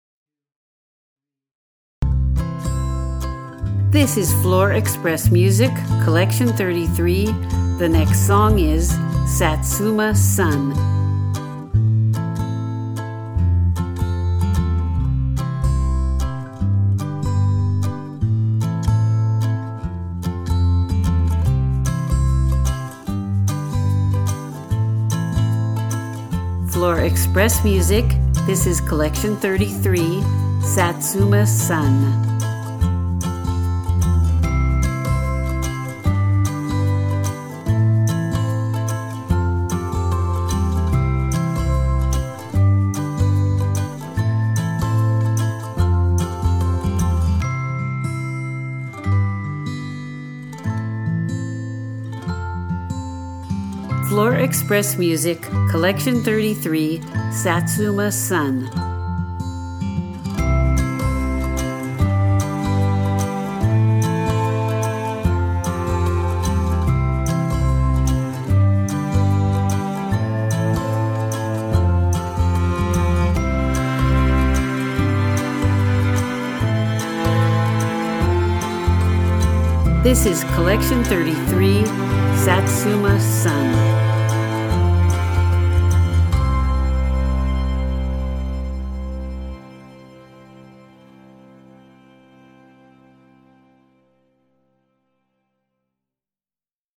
• Ukelele
• Caribbean